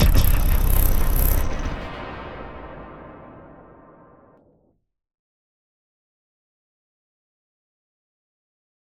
Processed Hits 25.wav